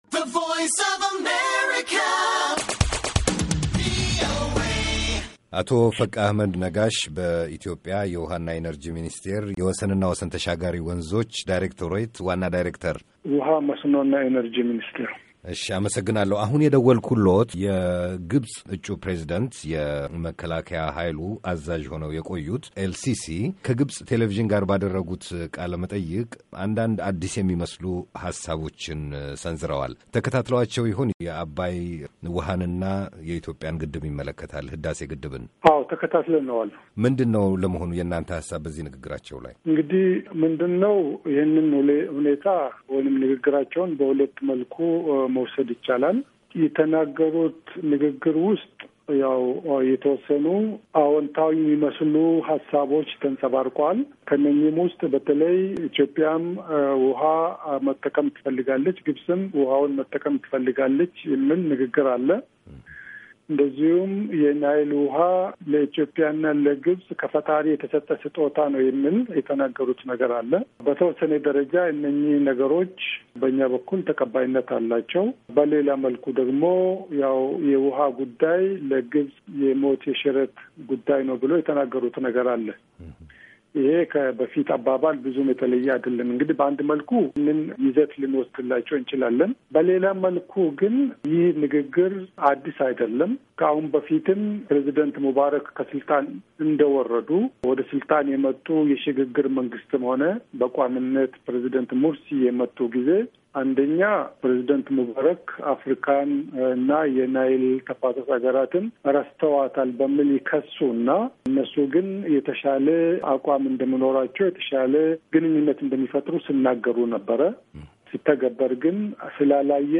ለቪኦኤ የሰጡት ቃለምልልስ ሙሉ ቃል፡፡